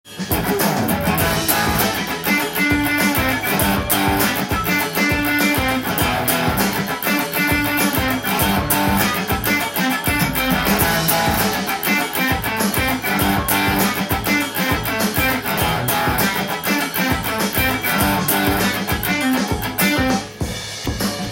更にAmペンタトニックスケールでカッティングをしパワーコードも混ぜると
洋楽系のAmワンコードの伴奏の完成です。